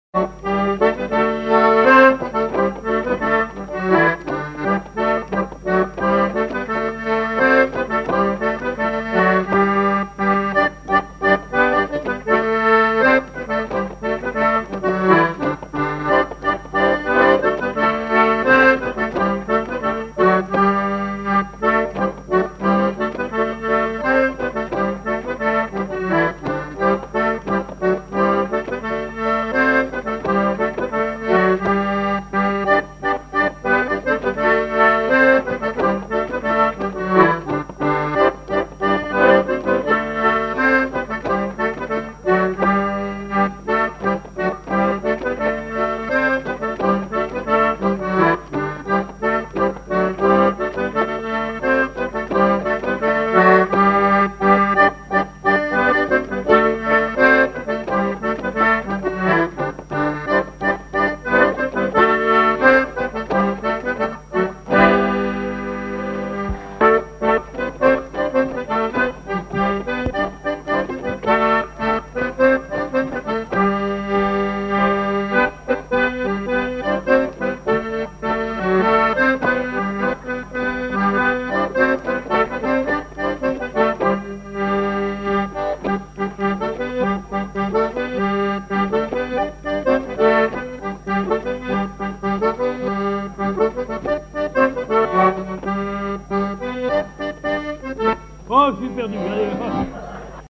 danse : scottich sept pas
collectif de musiciens pour une animation à Sigournais
Pièce musicale inédite